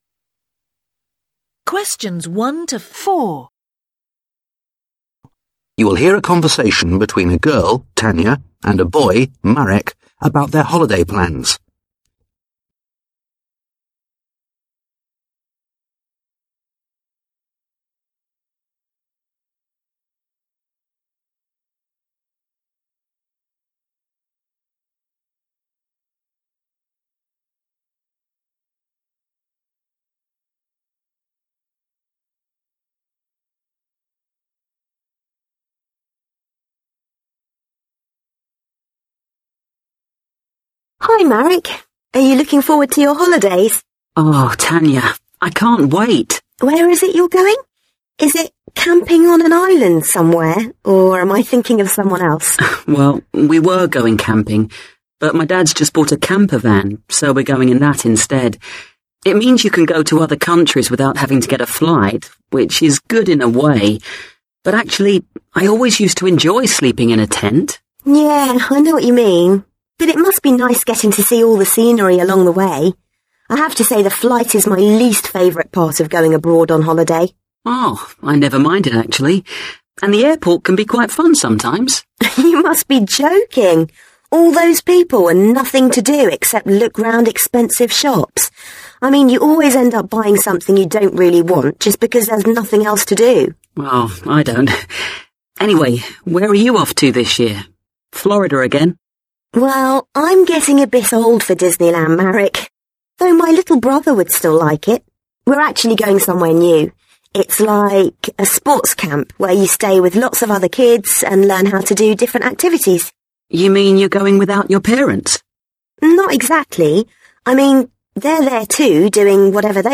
Conversation 1: You will hear a conversation between a girl, Tanya, and a boy, Marek, about their holiday plans.
Conversation 2: Listen to part of a radio interview with a man who is the director of an Environmental Centre.